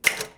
Tecla de una máquina de escribir
máquina de escribir
Sonidos: Oficina